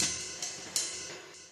Closed Hats
RIDE_LOOP_3.wav